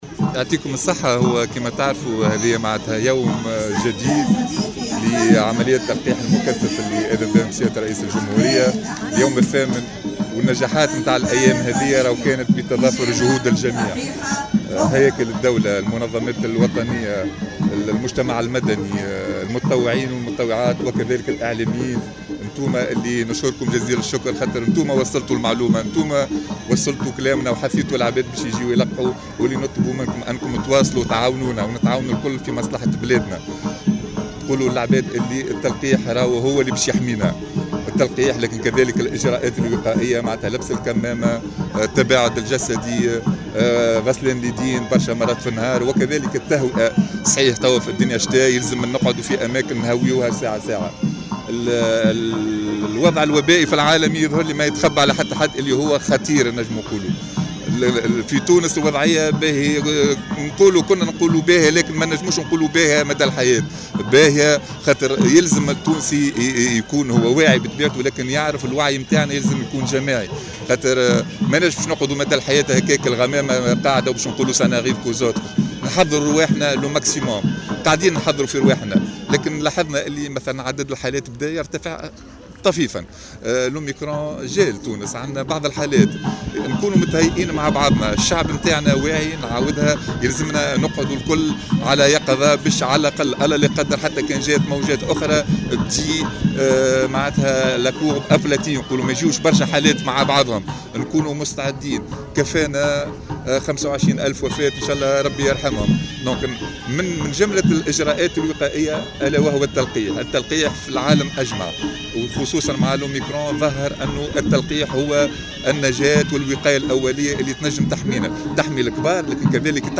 شدد وزير الصحة علي مرابط في تصريح للجوهرة أف أم، اليوم السبت، على ضرورة مواصلة اليقظة لتفادي موجات جديدة من فيروس كورونا، واصفا الوضع الوبائي في البلاد بالجيد، على الرغم من تسجيل ارتفاع طفيف في عدد الإصابات بالفيروس في الآونة الأخيرة.
وأكد مرابط لدى مواكبته لليوم الثامن من حملة التلقيح المكثف بمركز التطعيم بقبة المنزه، على ضرورة الإقبال على التلقيح ضد الفيروس باعتباره الوسيلة الأنجع للحماية من الكورونا، خاصة مع تفشي متحور أوميكرون في عديد الدول، وبعد أن تبين أنه قادر على إصابة الأطفال.